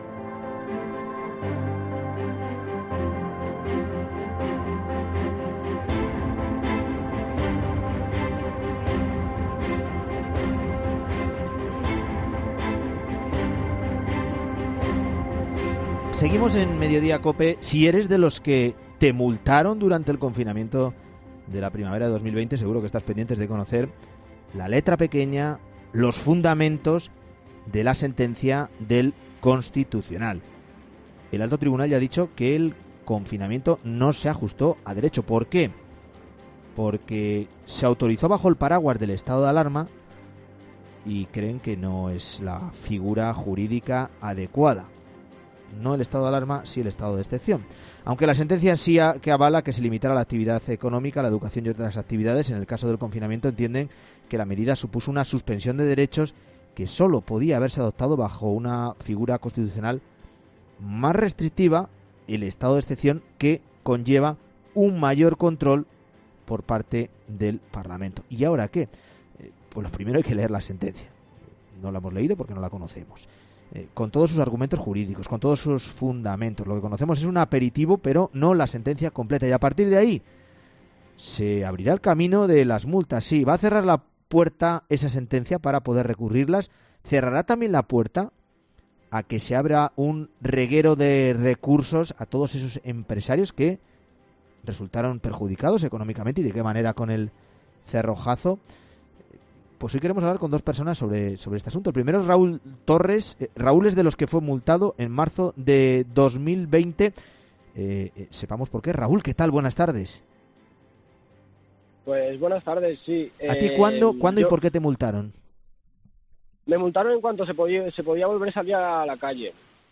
Hoy queremos hablar con dos personas sobre este tema.